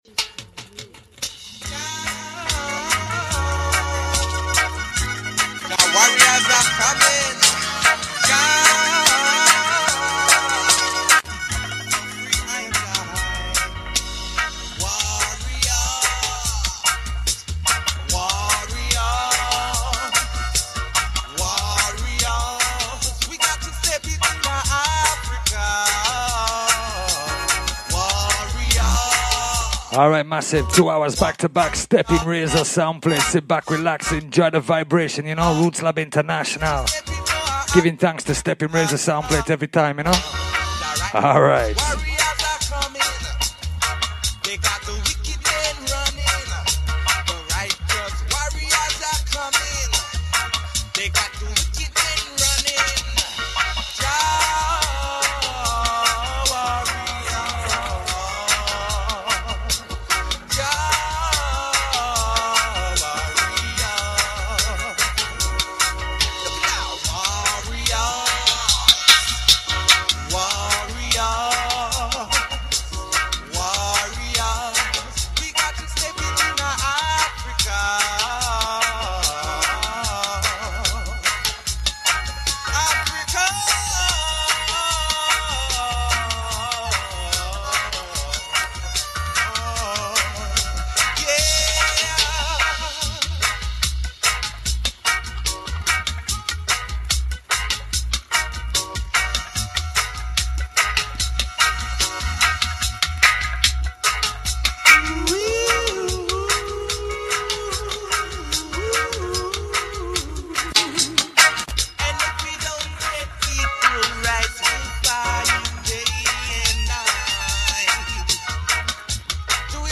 FOR THE BEST IN ROOTS & CULTURE / DUB / STEPPAS VIBES